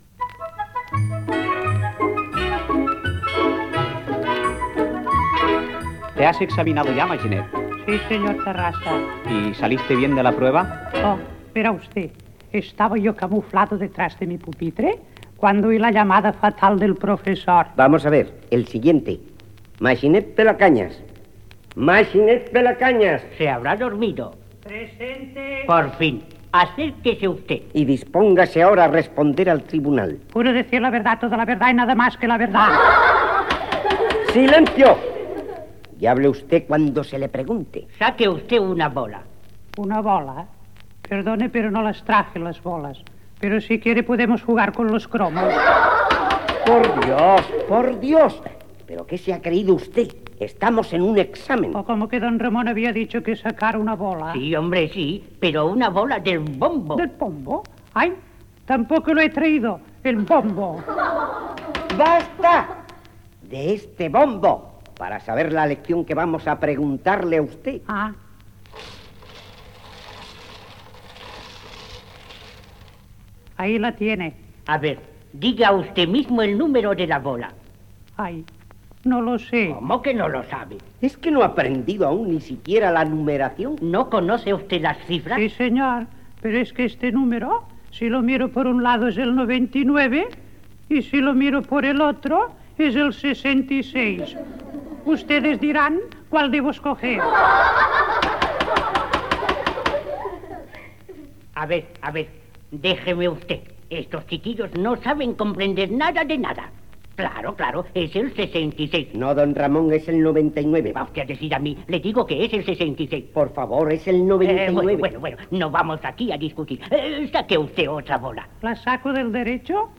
Infantil-juvenil
Extret del disc d'Emi-Odeón publicat amb motiu dels 20 anys de la REM l'any 1973.